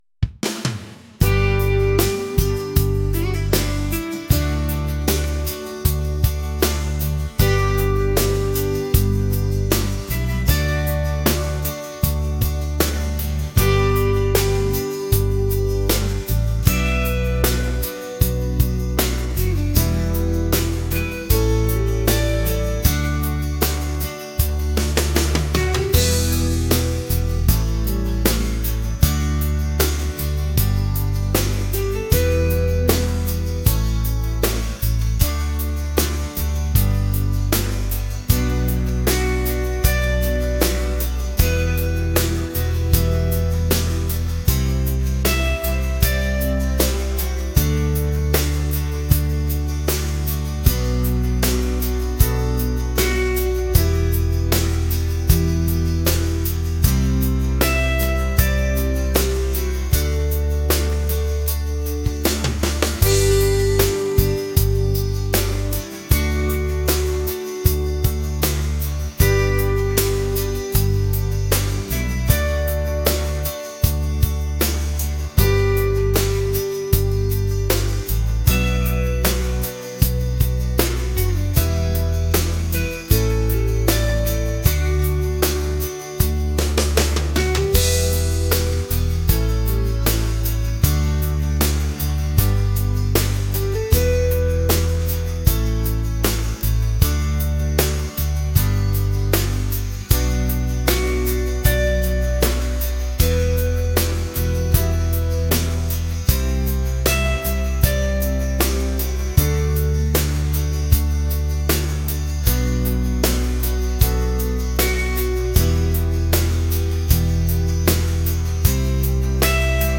smooth | laid-back | rock